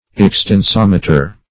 Search Result for " extensometer" : The Collaborative International Dictionary of English v.0.48: Extensometer \Ex`ten*som"e*ter\, n. [Extension + -meter.] An instrument for measuring the extension of a body, especially for measuring the elongation of bars of iron, steel, or other material, when subjected to a tensile force.